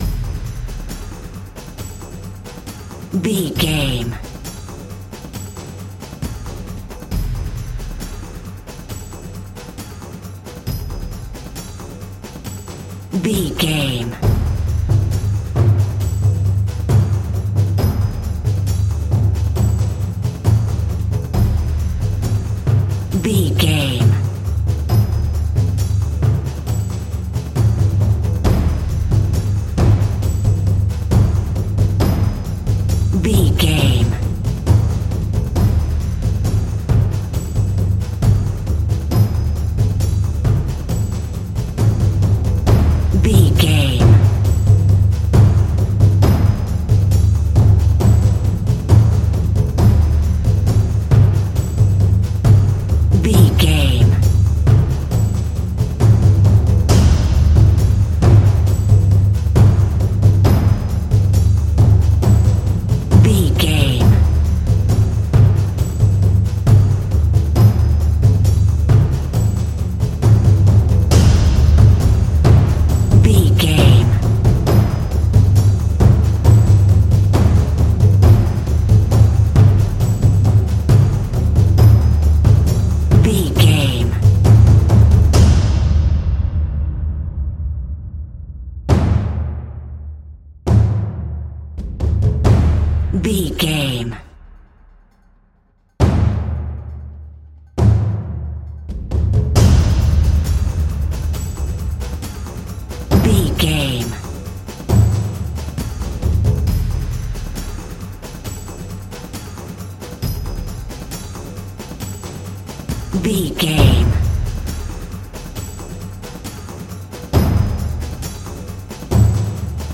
Exotic and world music!
Fast paced
Atonal
D
world beat
strings
brass
percussion
taiko drums
timpani